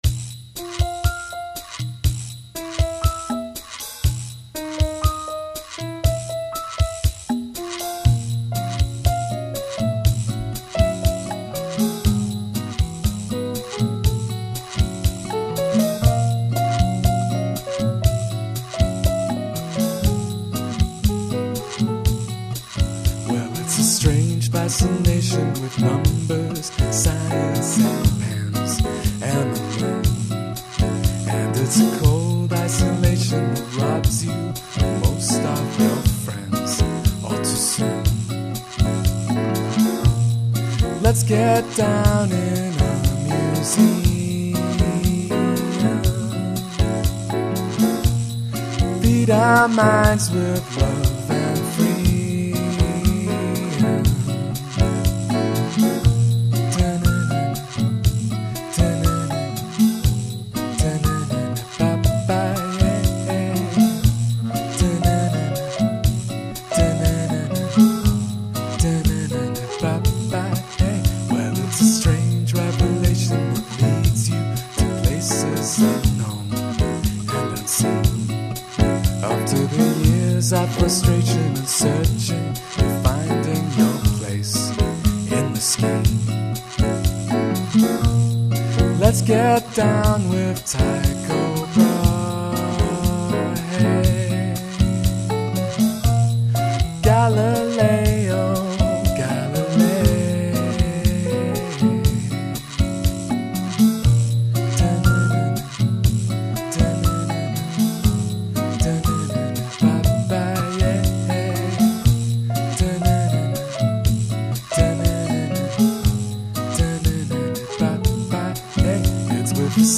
Folky guitars, jazzy melodies, sweet harmonies.